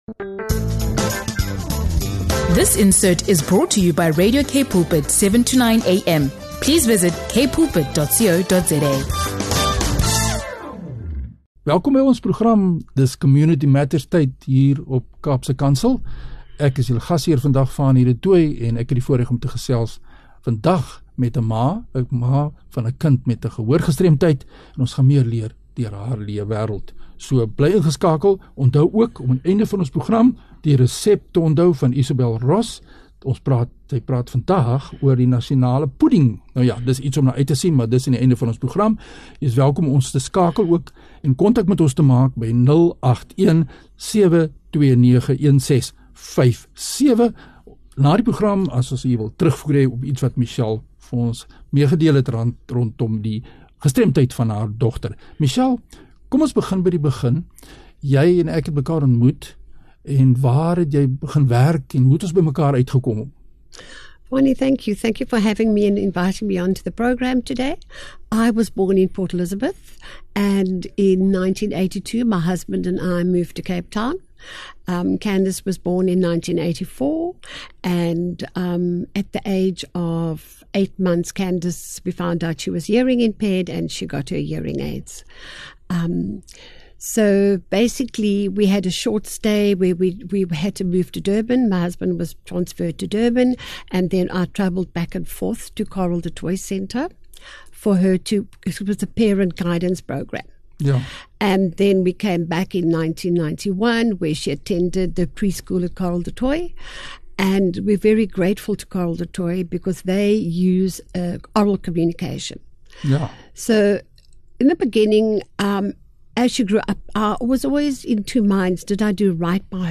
We explore the frustrations faced by people with hearing loss in the workplace and in the community, discussing how a lack of understanding and accessibility can make a significant difference. The conversation also highlights the importance of early identification of hearing loss in children and what community organizations are doing to improve accessibility in churches.